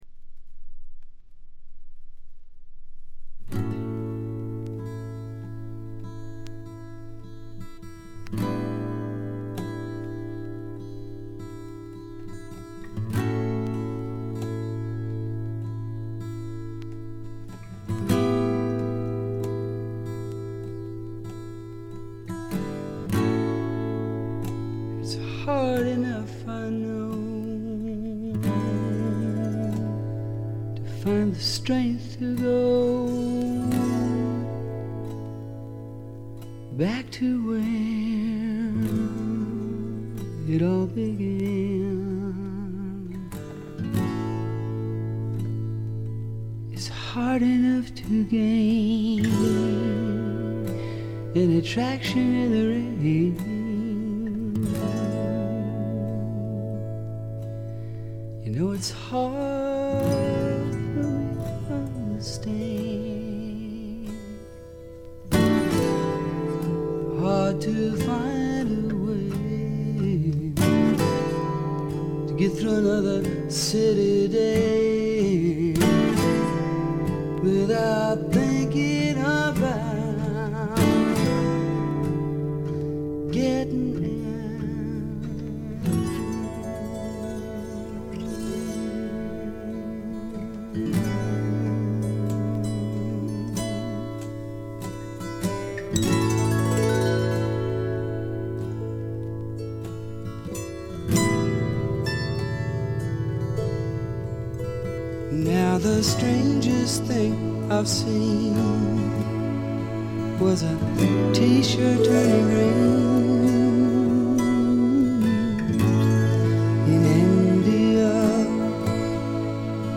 静音部で軽微なバックグラウンドノイズや軽微なチリプチ少々。
試聴曲は現品からの取り込み音源です。